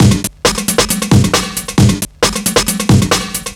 Hop Break 135.wav